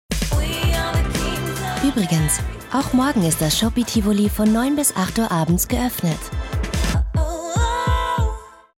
Reminder-Spot
Werbespots Radio